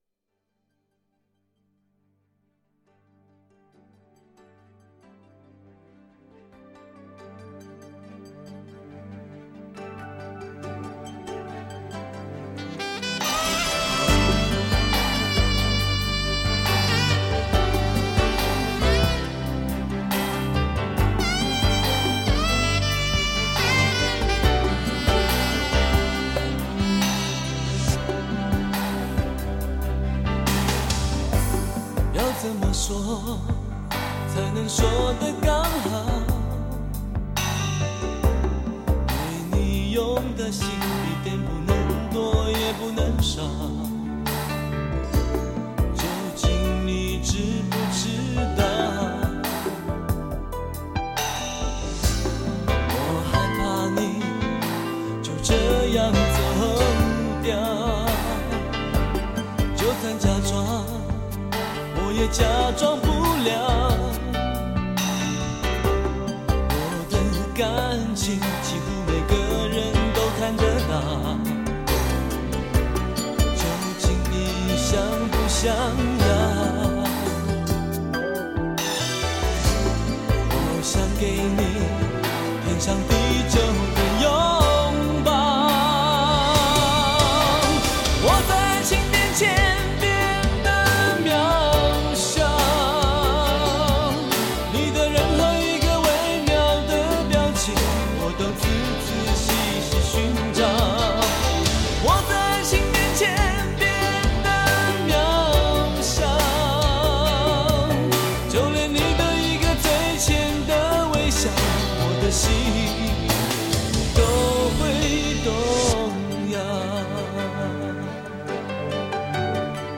在滤去了少年的青涩和疑问之后，这张专辑整个地散发着一股深沉的气息。